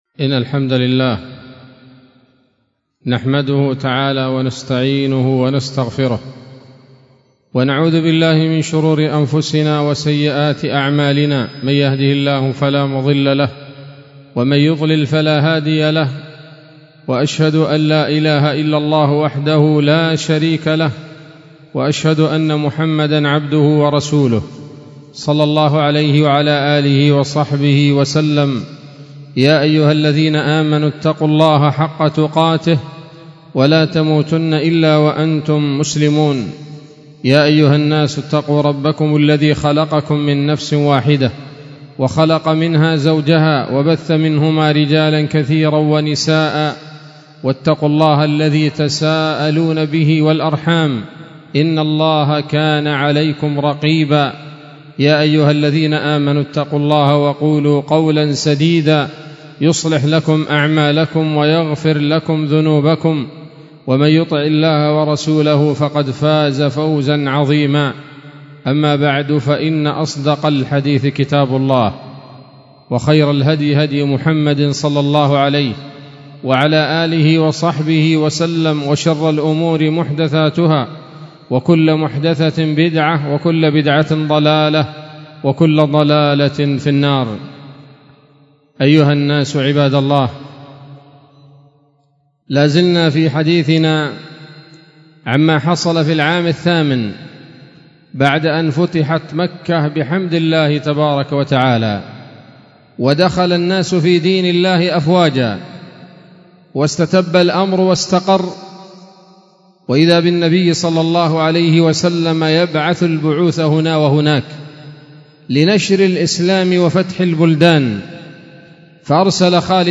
خطبة جمعة بعنوان: (( السيرة النبوية [30] )) 12 صفر 1446 هـ، دار الحديث السلفية بصلاح الدين